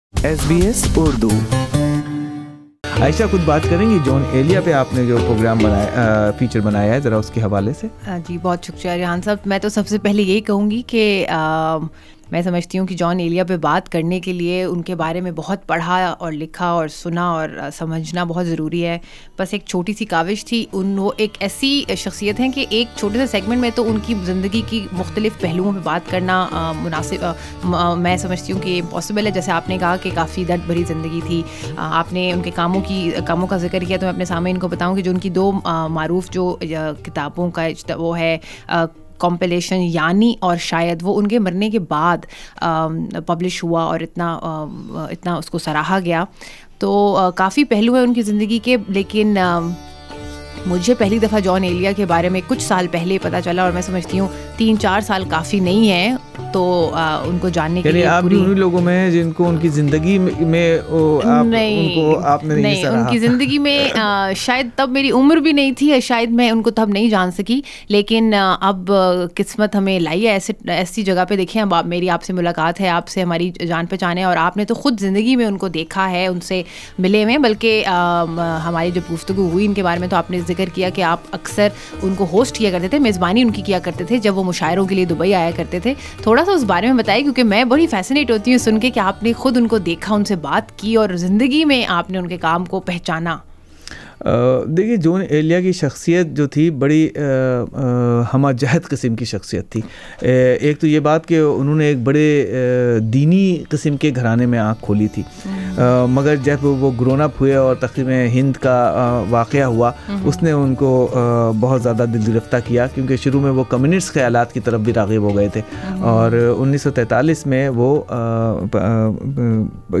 SBS Urdu talked to Pakistani-Australian writer